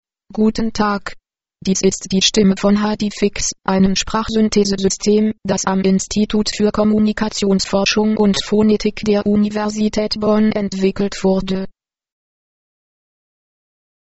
90. German female voice. 0.13